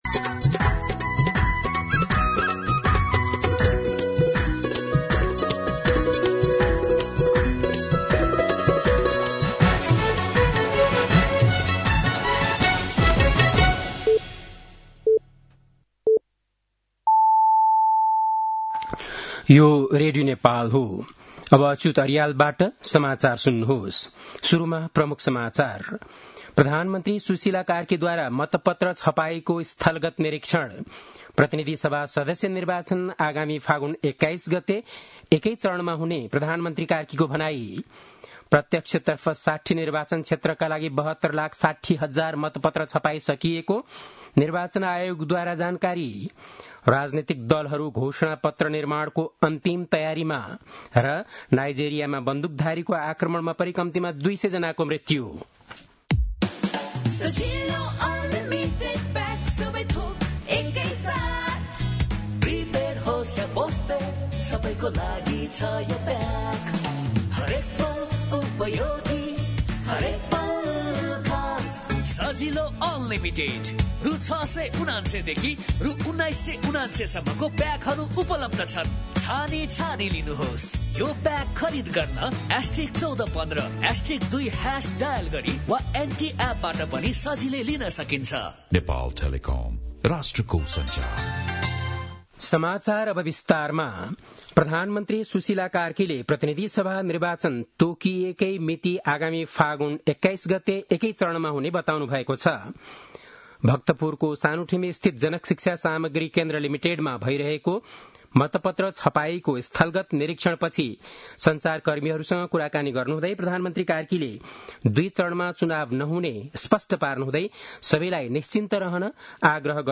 बेलुकी ७ बजेको नेपाली समाचार : २२ माघ , २०८२
7-pm-nepali-news.mp3